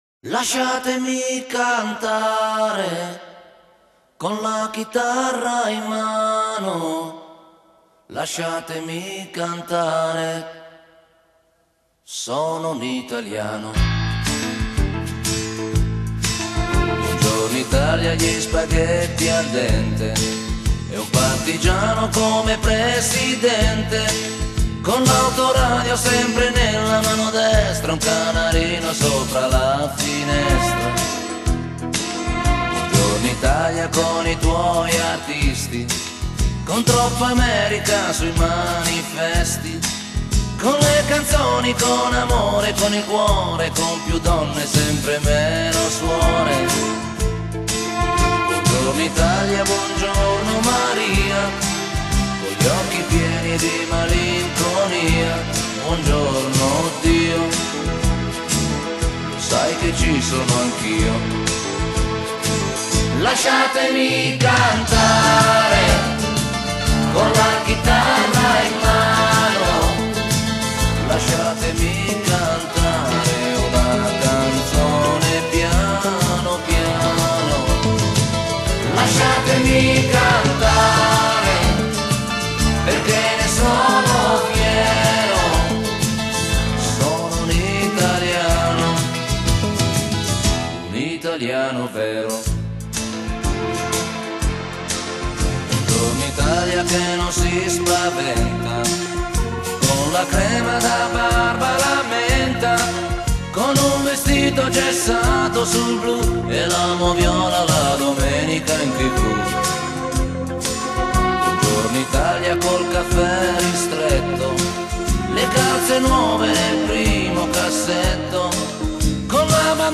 Genre: Pop | FS